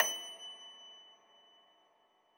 53k-pno24-F5.wav